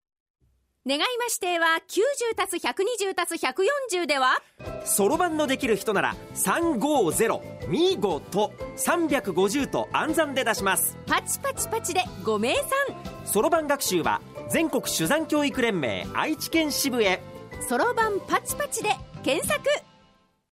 ＞東海ラジオ放送ラジオコマーシャル音源（MP3）